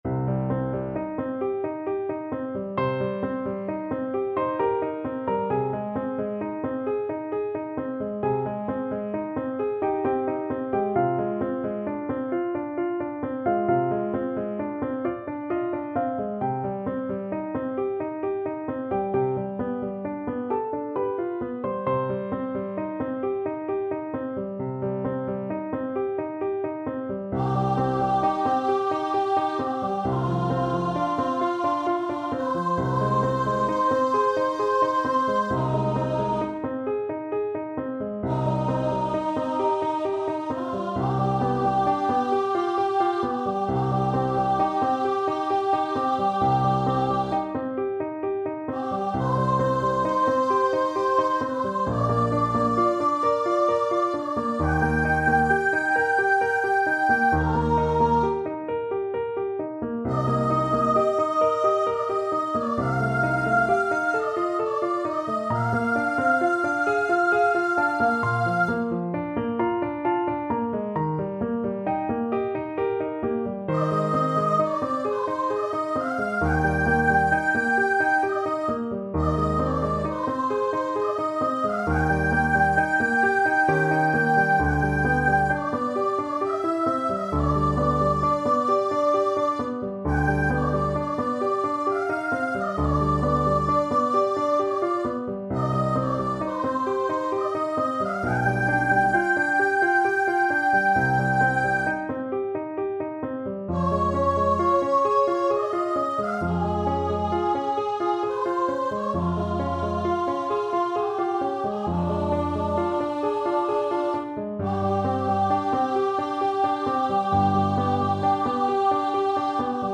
~ = 100 =66 Andante
3/4 (View more 3/4 Music)
Classical (View more Classical Soprano Voice Music)